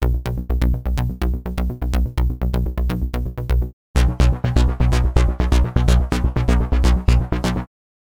这款震撼人心的鼓处理器采用您的节拍并通过强烈的效果运行它们，从而产生让您的听众大吃一惊的转换。